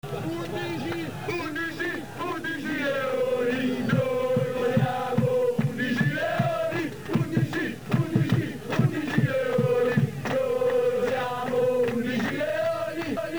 I cori in Mp3 degli Ultrà Lodigiani
Purtroppo l'acustica non è perfetta ma questo è quanto riusciamo a fare.
Partite varie in casa